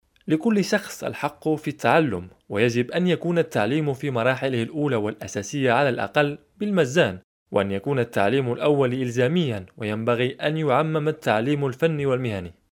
Arabic Moroccan voice over